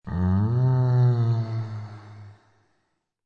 fx-mmm.mp3